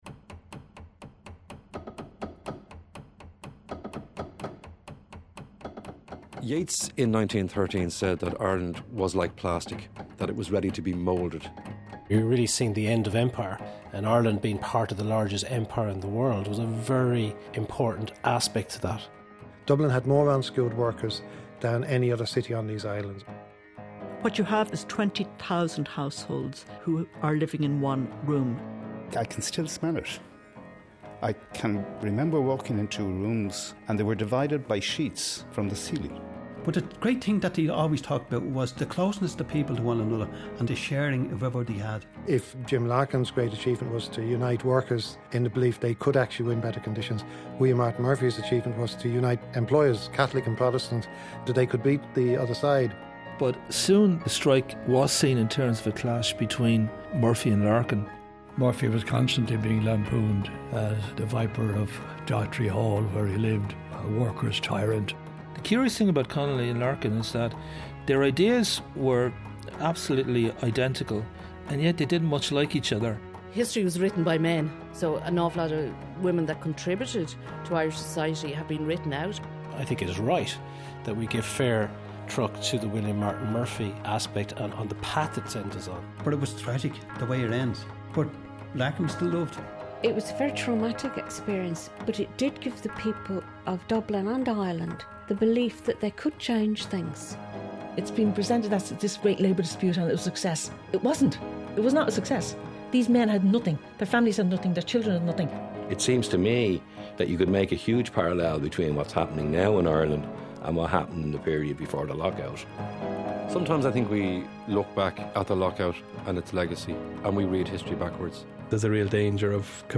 An audio history series made by Athena Media with the funding support of the BAI. The series relates the causes, consequences and legacy of the capital versus labour conflict.